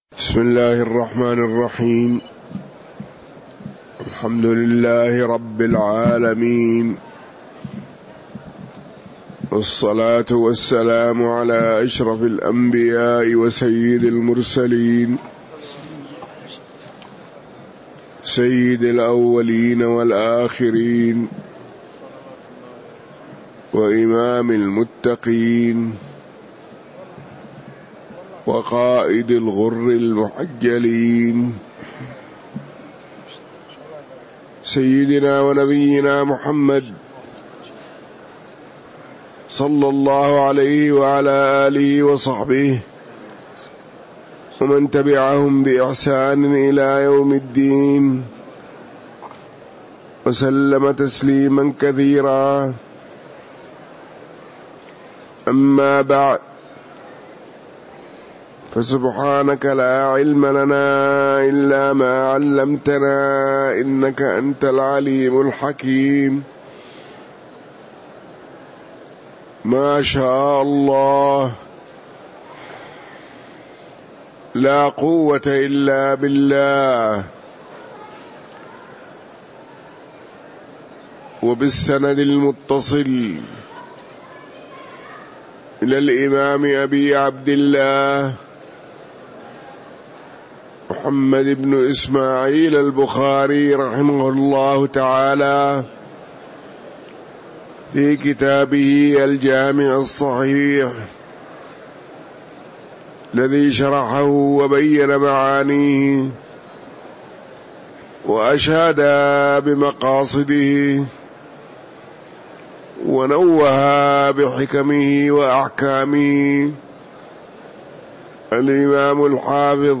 تاريخ النشر ٢٣ ربيع الأول ١٤٤٥ هـ المكان: المسجد النبوي الشيخ